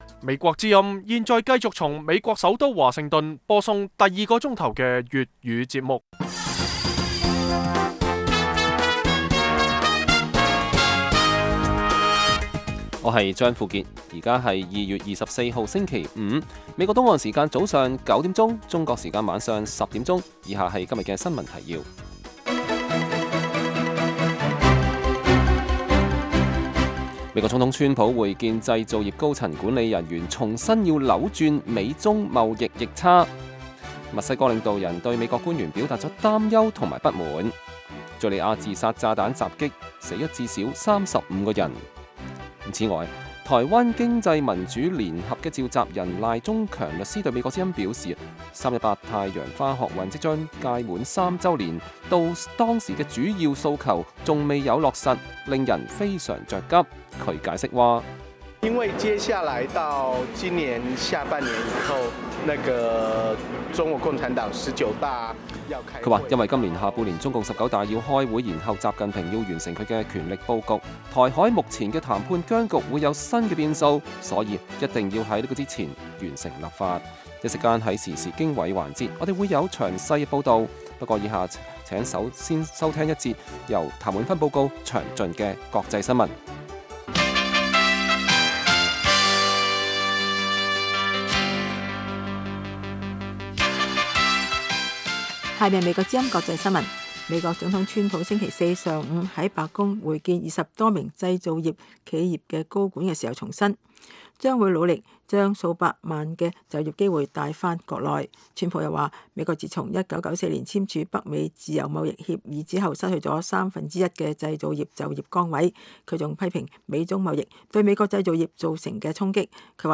北京時間每晚10－11點 (1400-1500 UTC)粵語廣播節目。內容包括國際新聞、時事經緯和社論。